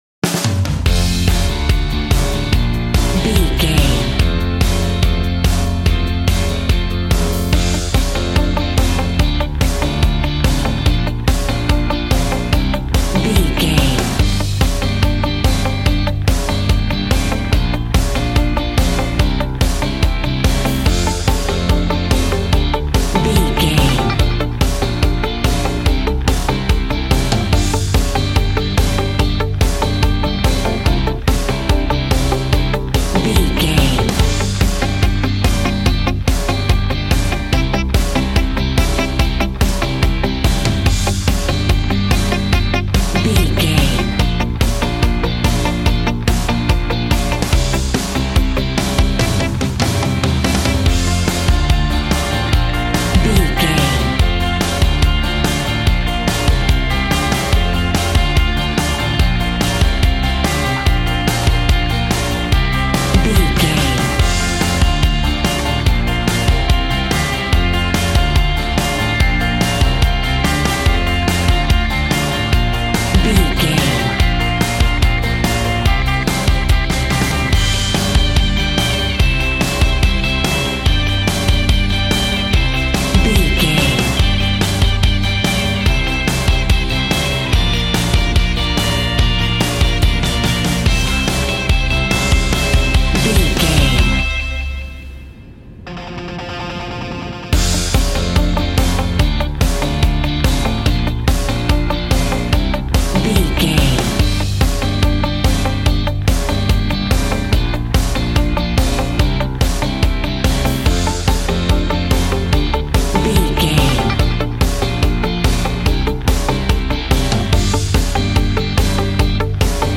Aeolian/Minor
groovy
happy
electric guitar
bass guitar
drums
piano
organ